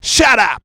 SHUT UP.wav